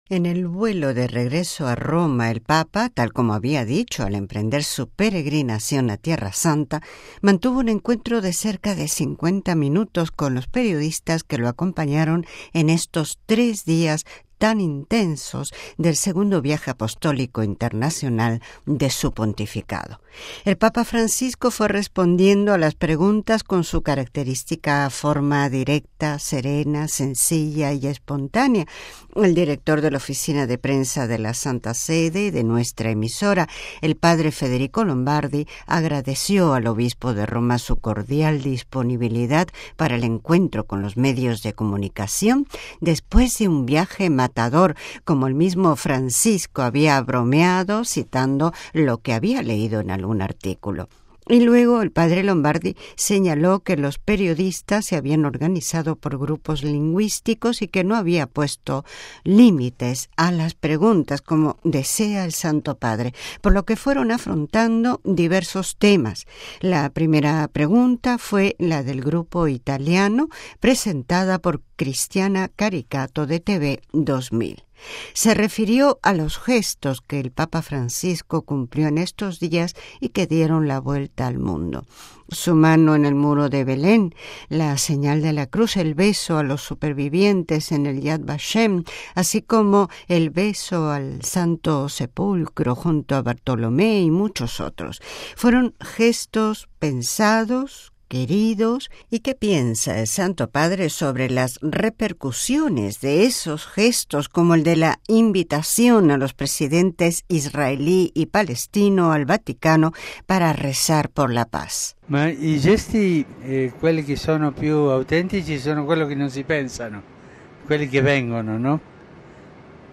MP3 En el vuelo de regreso a Roma, el Papa, tal como había dicho al emprender su Peregrinación a Tierra Santa, mantuvo un encuentro de cerca de 50 minutos con los periodistas que lo acompañaron en estos tres días tan intensos, del II Viaje Apostólico internacional de su Pontificado. El Papa Francisco fue respondiendo a las preguntas con su característica forma directa, serena, sencilla y espontánea.